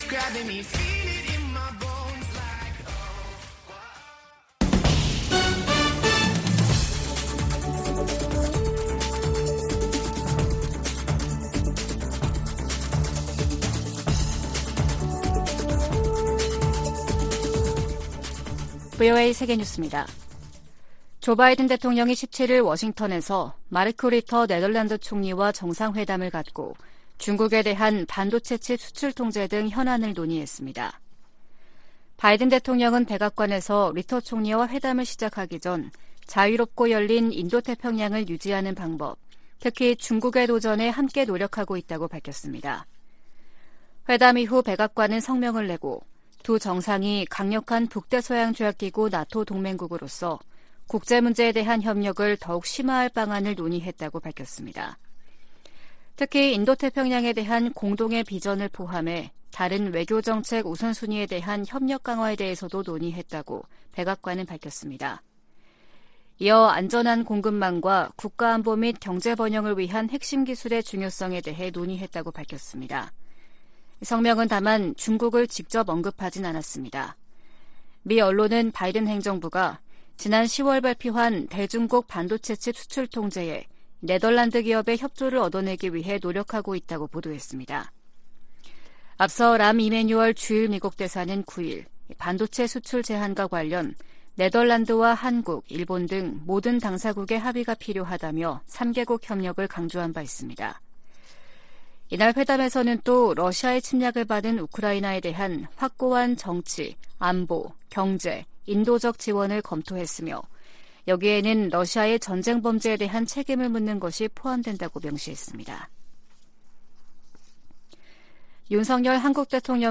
VOA 한국어 아침 뉴스 프로그램 '워싱턴 뉴스 광장' 2023년 1월 18일 방송입니다. 미국 해군참모총장은 한국의 ‘자체 핵무장’ 안과 관련해 미국의 확장억제 강화를 현실적 대안으로 제시했습니다. 유엔은 핵보유국 의지를 재확인한 북한에 긴장 완화를 촉구하고 유엔 결의 이행과 외교를 북핵 문제의 해법으로 거듭 제시했습니다.